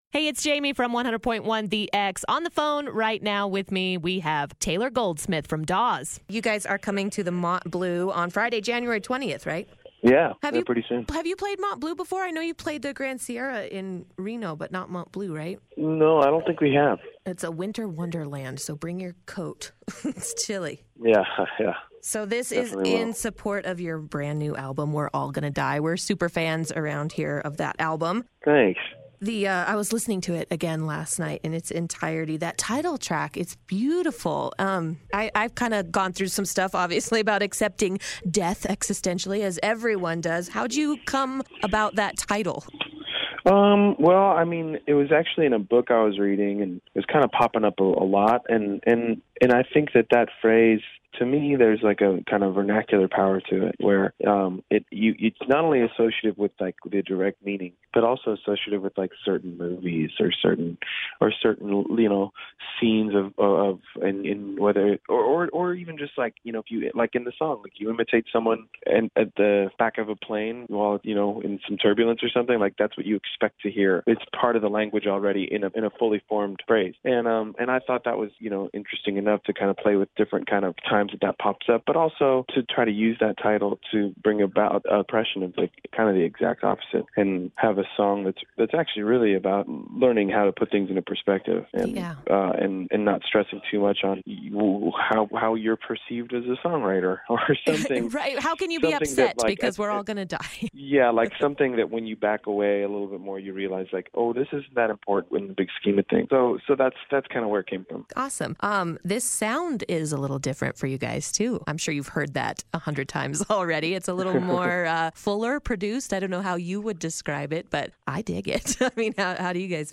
Taylor Goldsmith of Dawes Interview